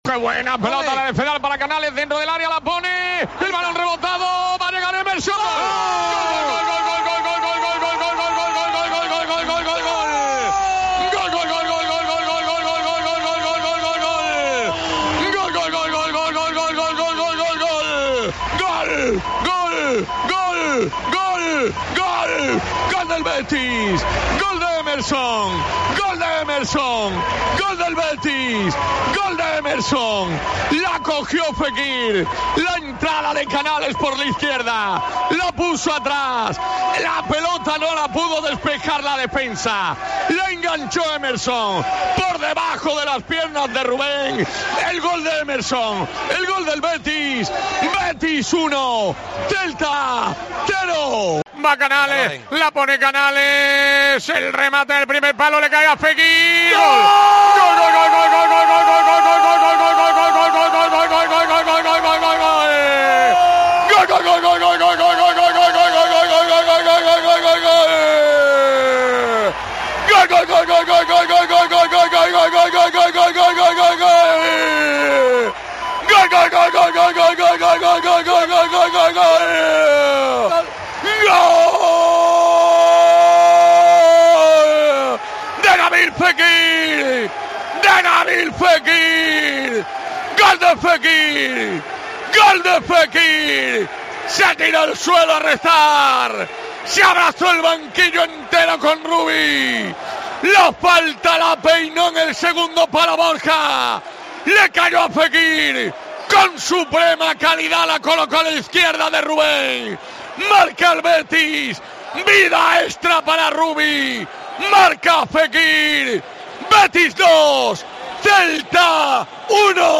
Los goles del Betis ante el Celta narrados por